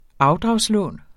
Udtale [ ˈɑwdʁɑws- ]